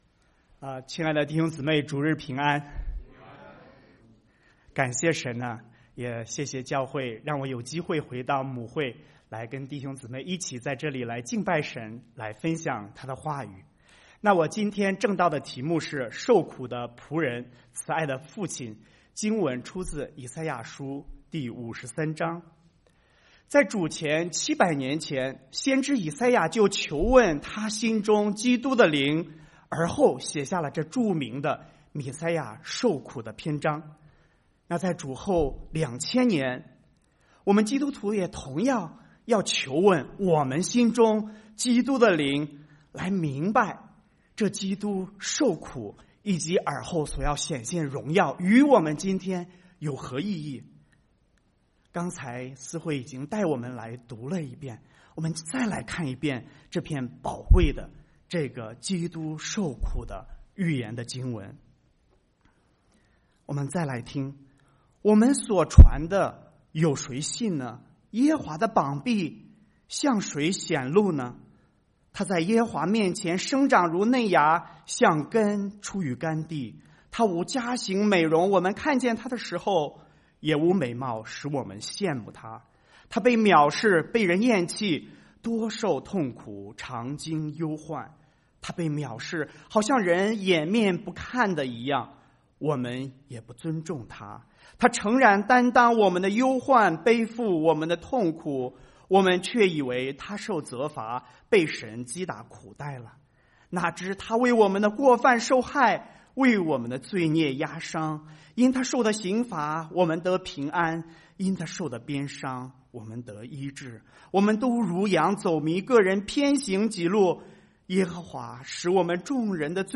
崇拜講道錄音